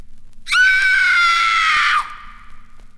• scream terrified - female.wav
scream_terrified_-_female_nHz.wav